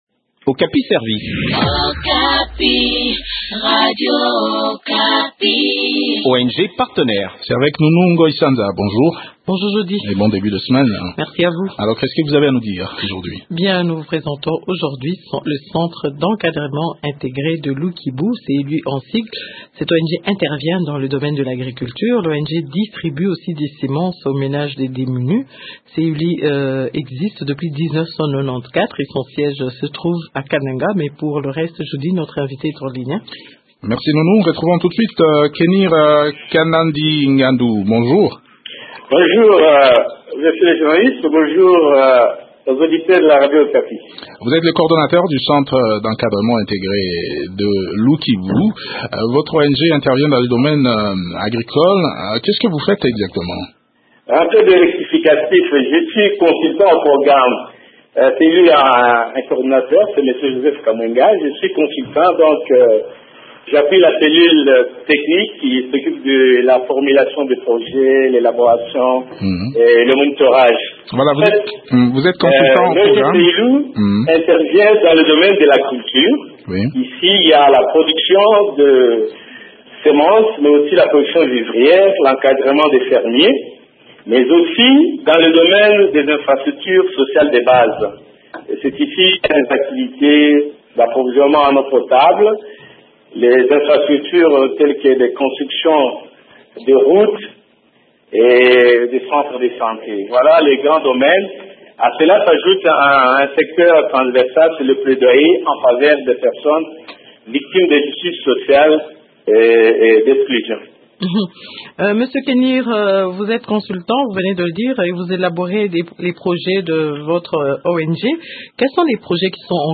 fait le point de leurs activités au micro de